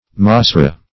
Masora \Ma*so"ra\, n. [NHeb. m[=a]s[=o]r[=a]h tradition.]